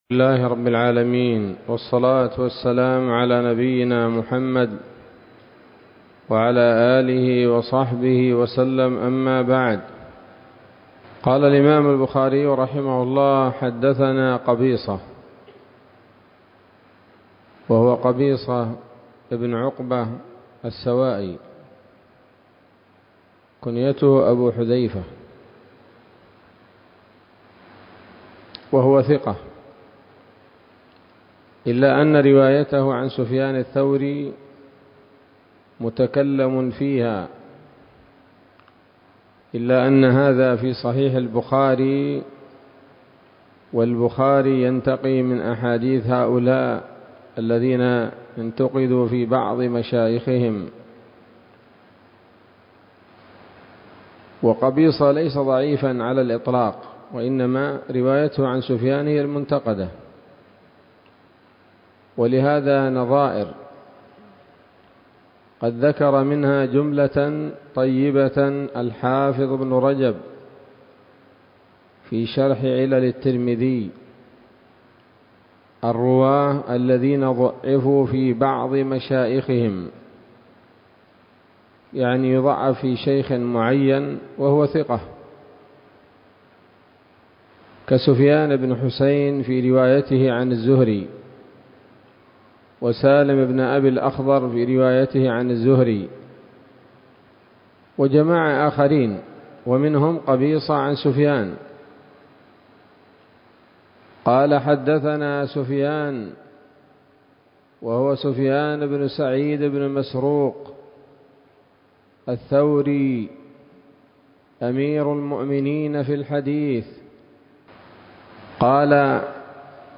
الدرس الثالث من كتاب الذبائح والصيد من صحيح الإمام البخاري